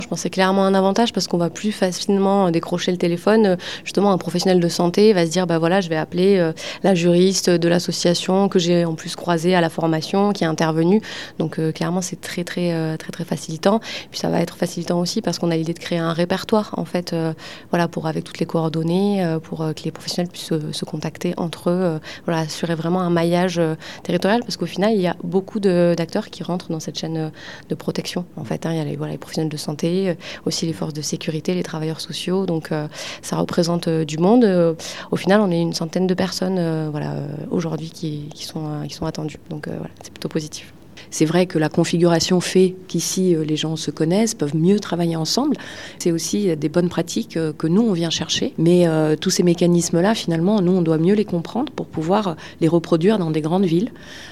Toute la journée dans l’amphithéâtre de l’Unîmes de Mende, les prises de paroles se sont succédées avec le même objectif : mieux accompagner les victimes de violences sexistes et sexuelles sur le département.